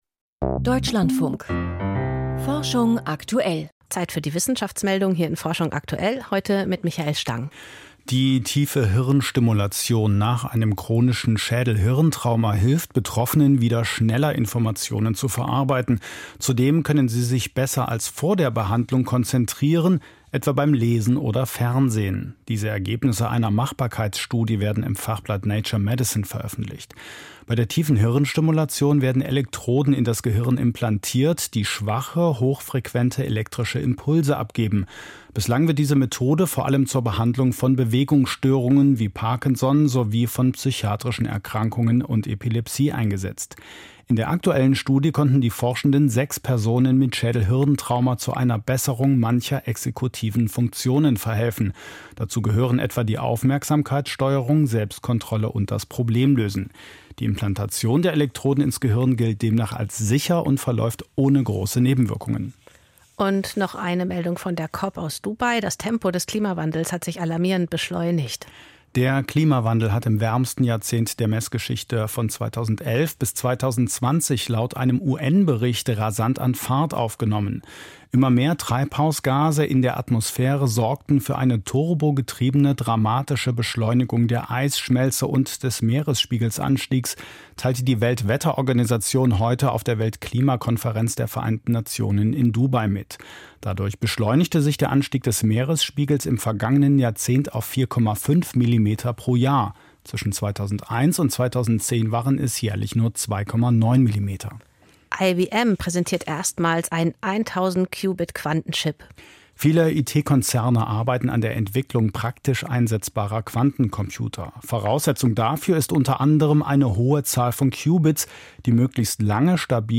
Wissenschaftsmeldungen 26.04.2024. Schon im Ei: Straßenlärm beeinträchtigt die Entwicklung von Jungvögeln.